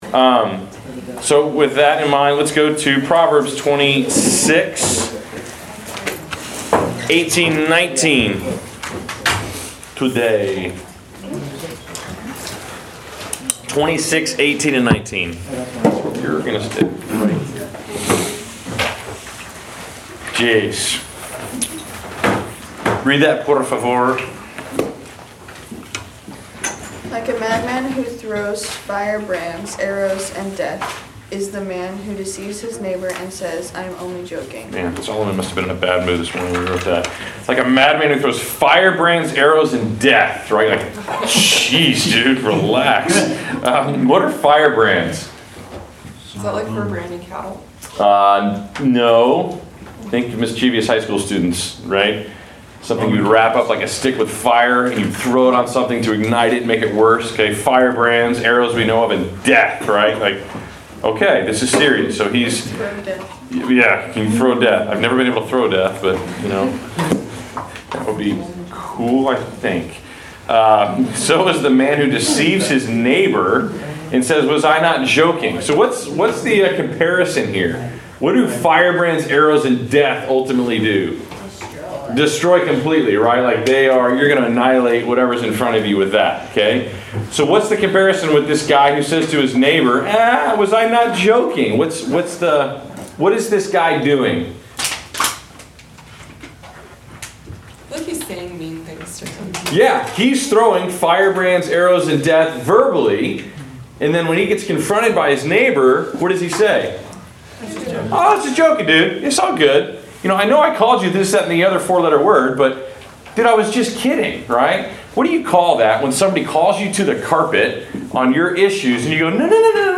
Class Session Audio